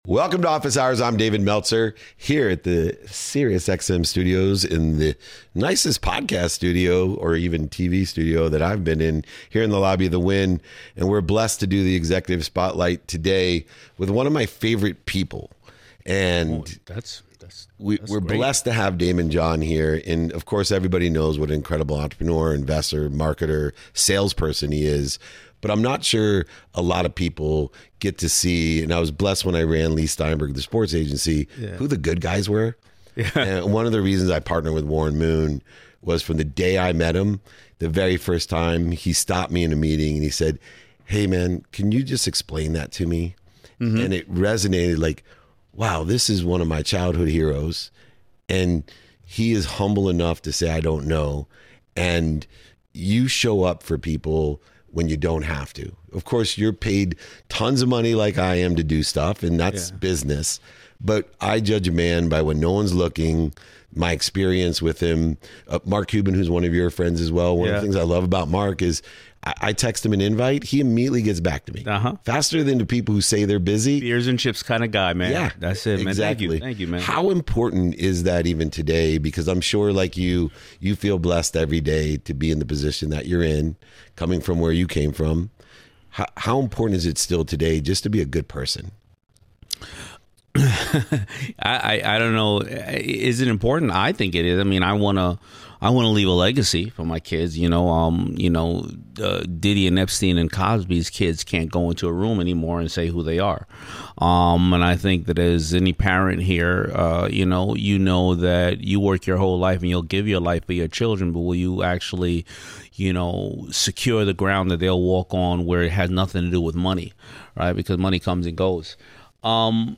In today’s episode, I sit down with Daymond John, the founder of FUBU and longtime Shark Tank investor, to talk about the values, priorities, and decisions that have shaped his life and career.